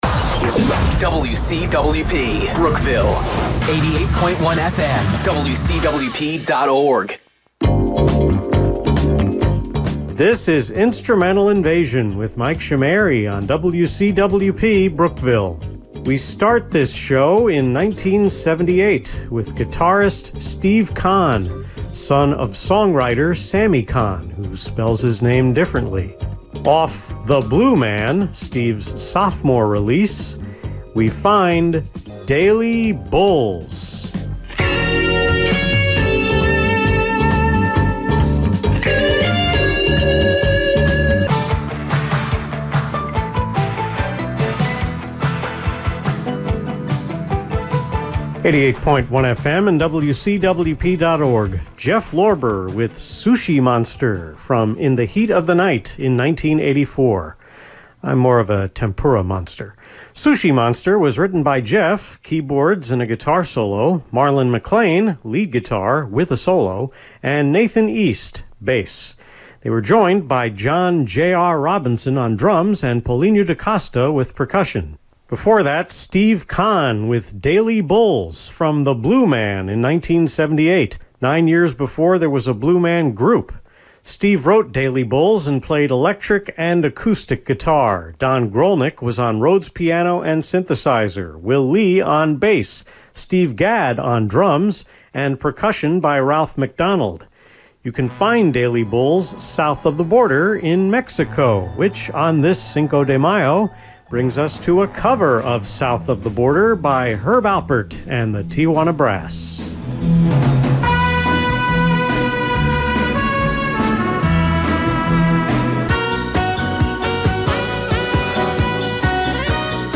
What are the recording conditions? The May 5 Instrumental Invasion on WCWP was recorded two segments per day from March 31 to April 2.